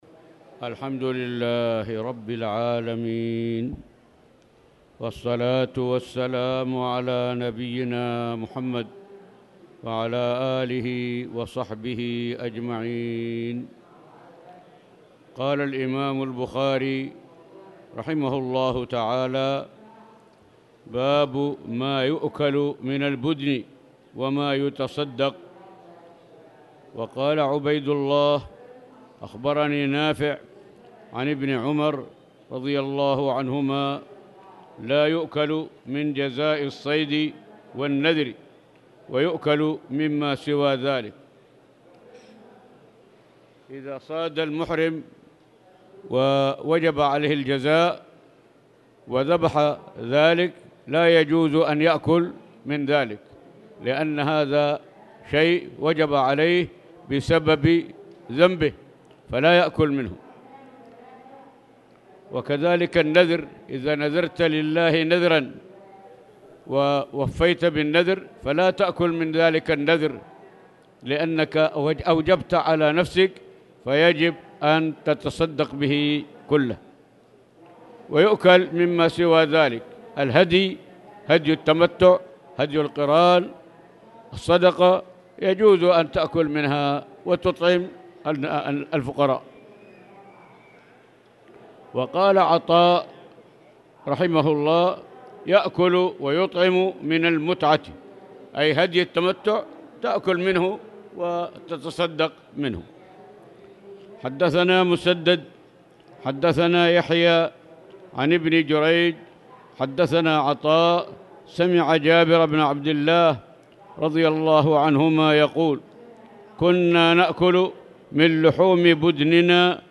تاريخ النشر ٢٣ صفر ١٤٣٨ هـ المكان: المسجد الحرام الشيخ